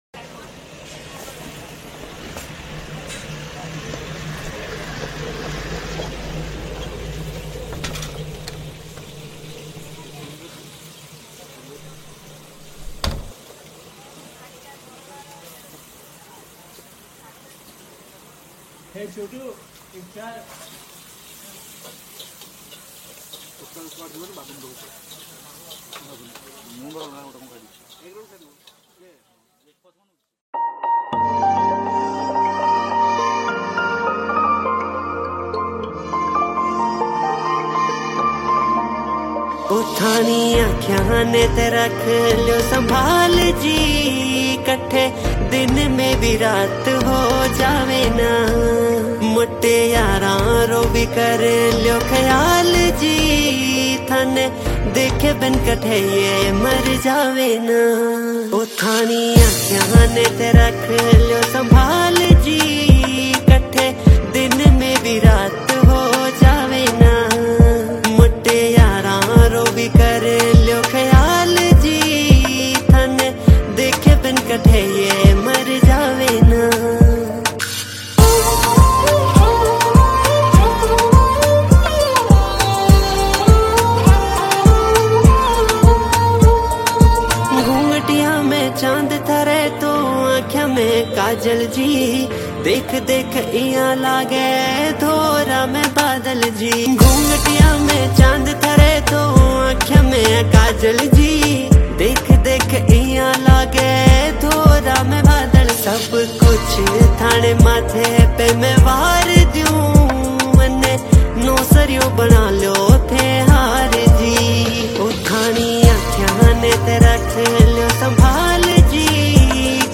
Category: Rajasthani